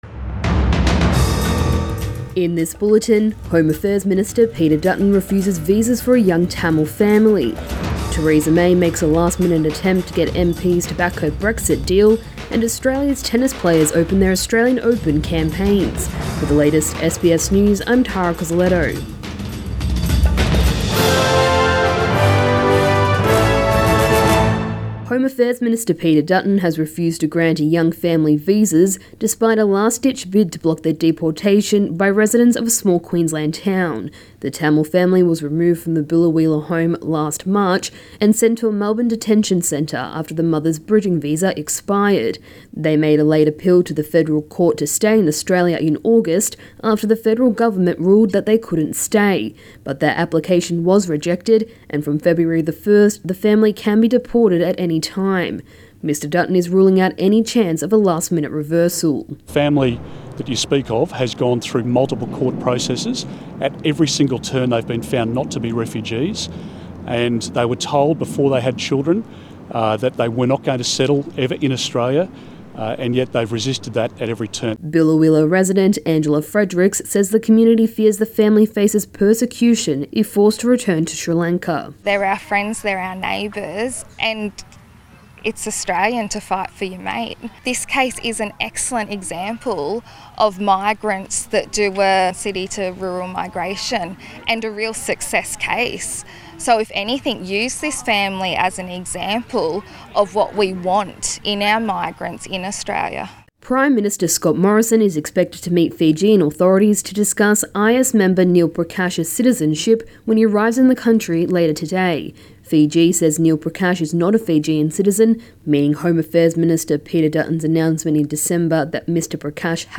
AM bulletin 15 January